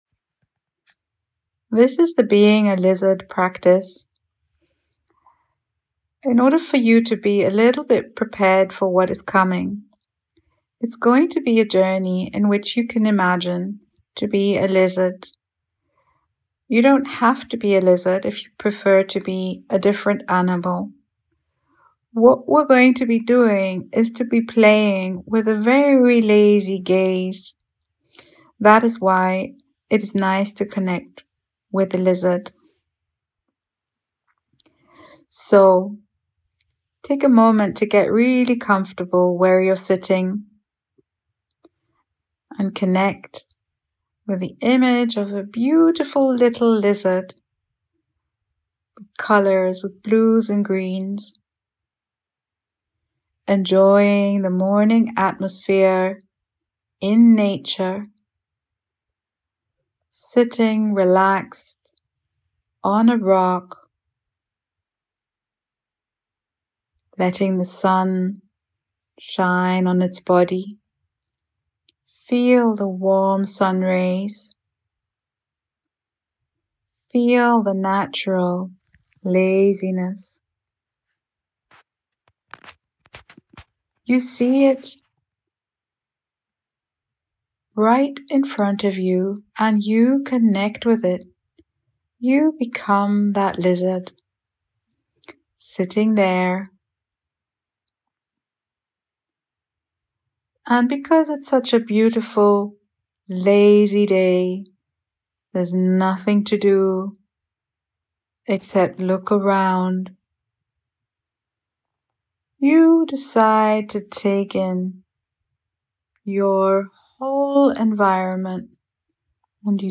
Being a Lazy Lizard Guided Imagery
A gentle guided imagery journey inspired by our work with eye movement and sensory awareness in sessions. You’ll be invited to imagine yourself as a lizard resting in the sun, your gaze soft and unhurried, taking in the scenery without effort.